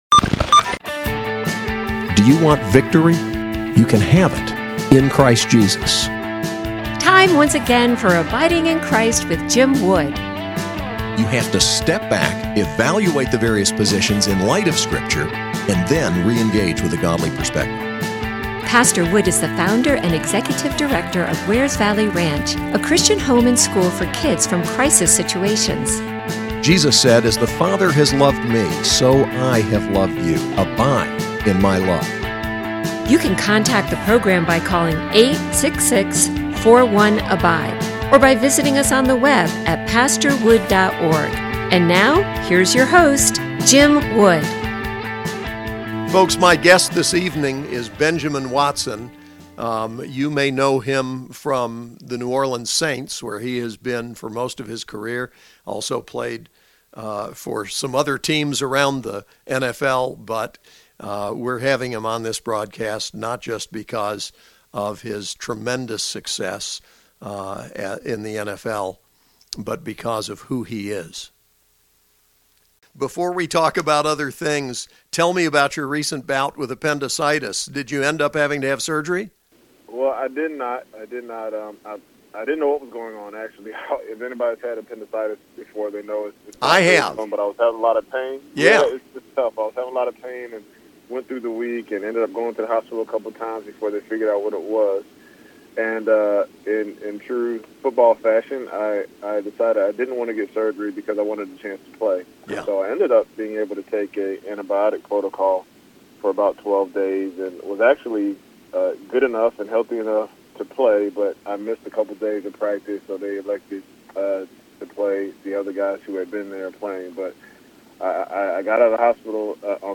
Interview with Benjamin Watson, NFL